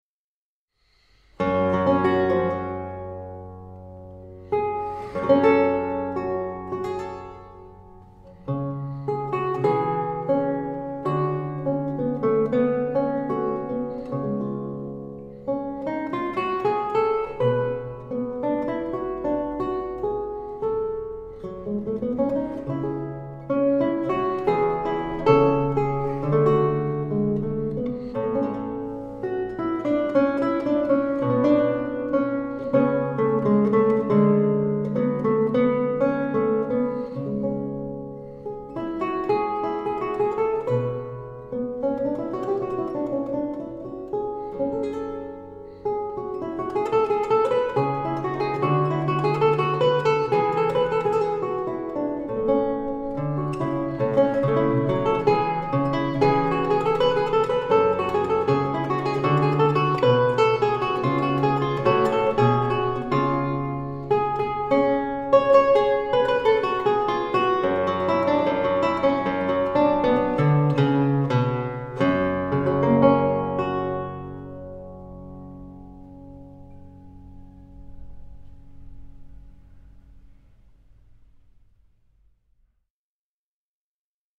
Klassische Gitarre
Lautenmusik der Renaissance